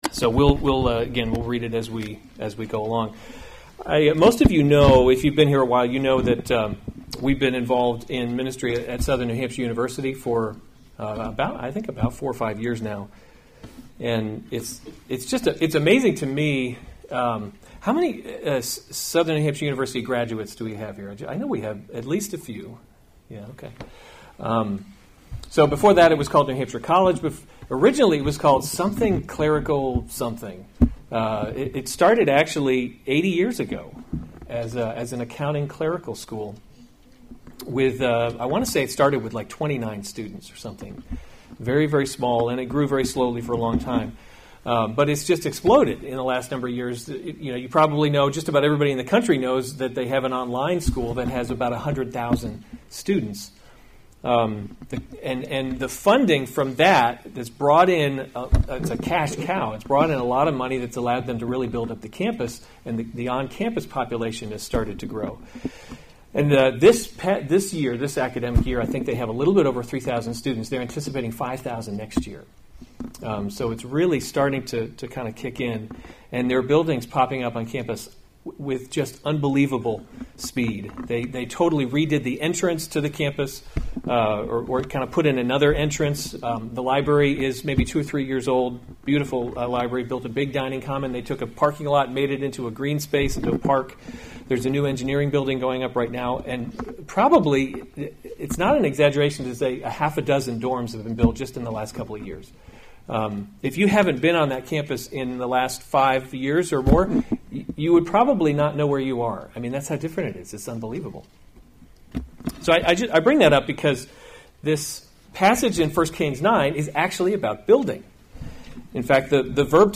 January 12, 2019 1 Kings – Leadership in a Broken World series Weekly Sunday Service Save/Download this sermon 1 Kings 9:1-28 Other sermons from 1 Kings The Lord Appears to […]